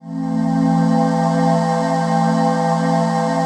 ATMOPAD16.wav